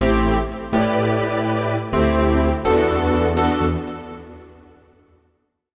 SYNTH015_VOCAL_125_A_SC3(R).wav